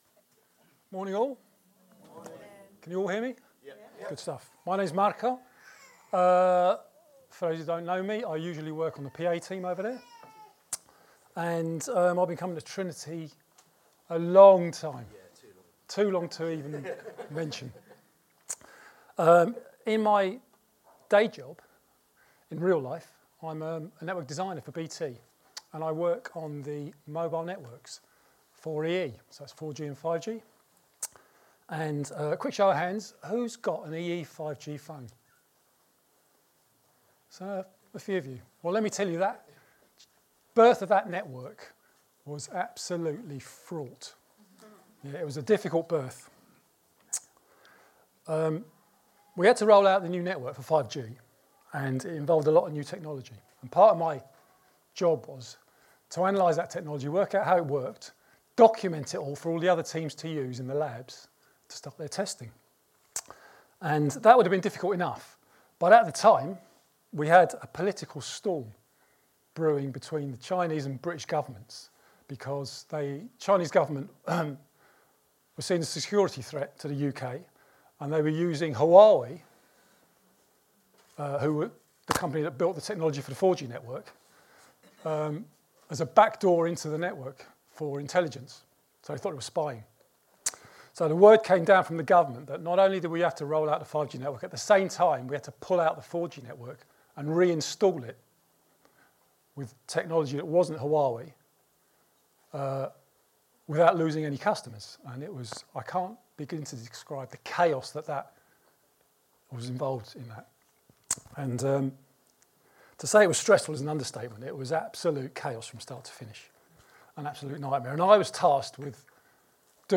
Download Sent into Work & Knowing Your Purpose | Sermons at Trinity Church